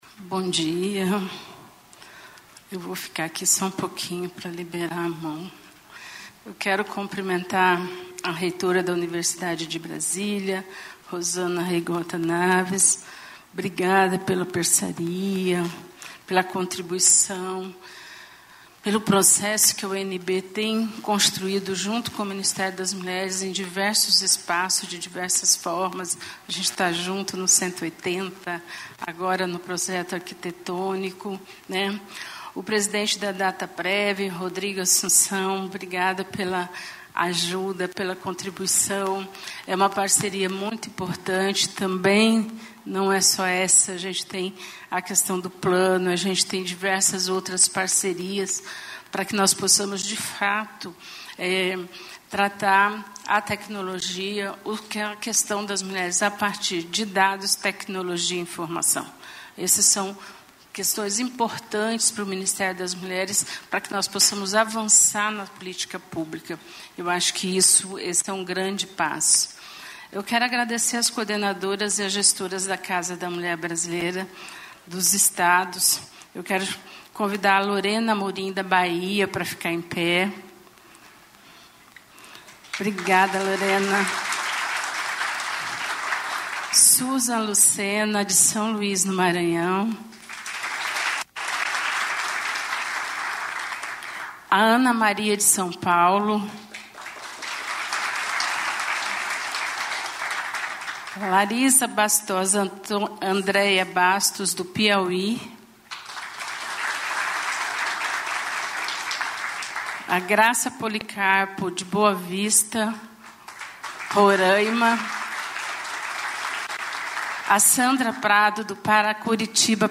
Íntegra do discurso do ministro do Desenvolvimento, Indústria, Comércio e Serviços, Geraldo Alckmin, na COP29 no segmento de alto nível, nesta terça-feira (12), em Baku no Azerbaijão.